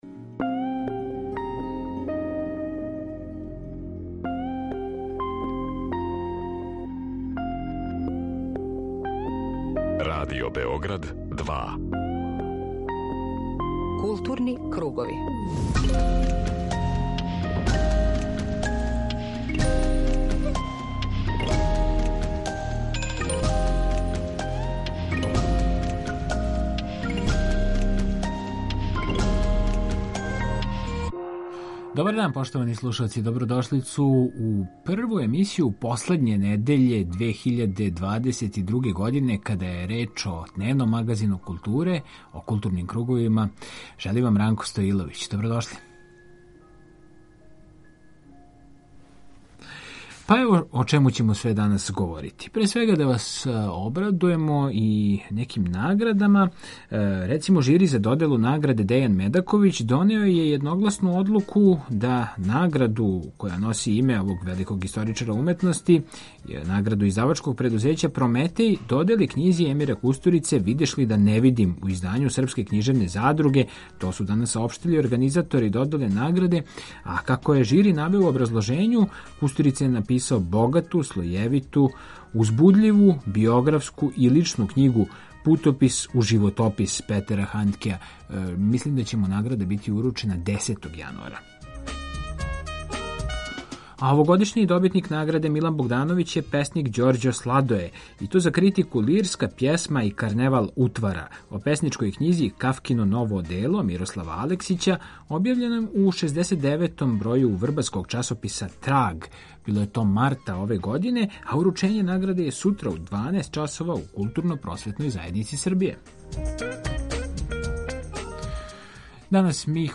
Извештавамо са представљања Изабраних дела академика Василија Ђ. Крестића у Српској академији наука и уметности.